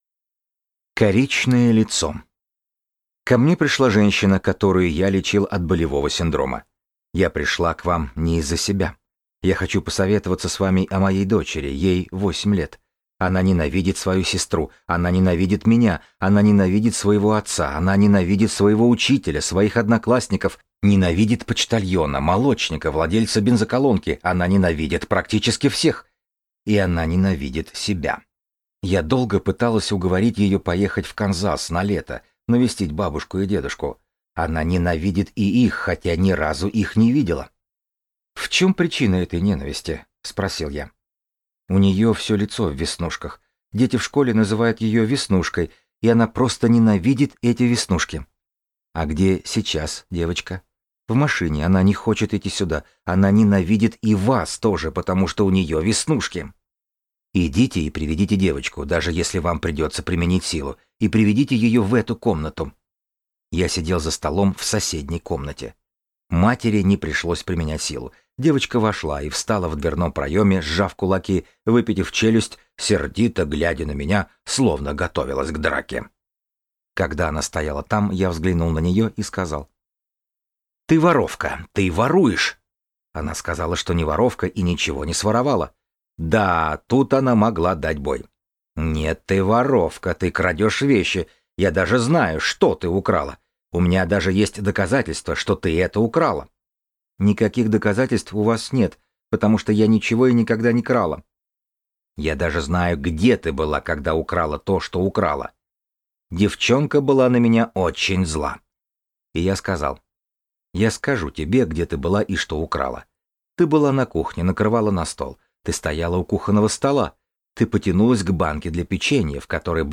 Аудиокнига Рефрейминг | Библиотека аудиокниг